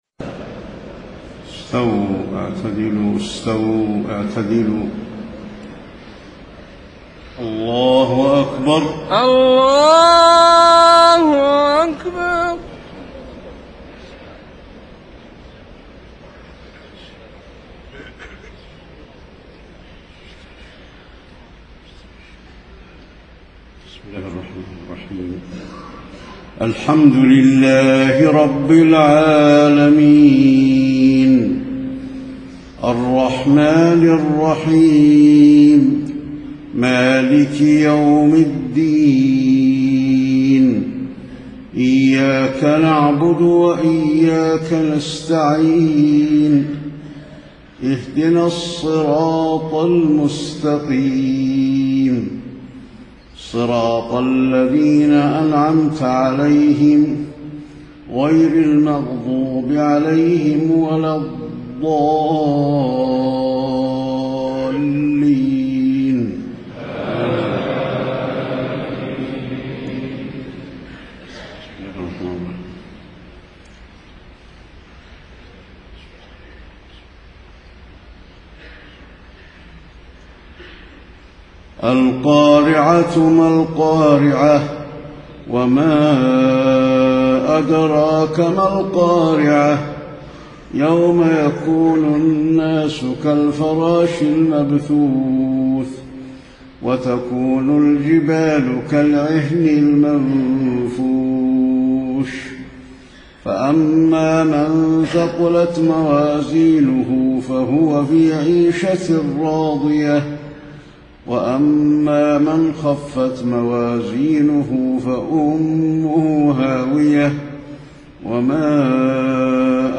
صلاة المغرب 8-5-1434 سورتي القارعة و التكاثر > 1434 🕌 > الفروض - تلاوات الحرمين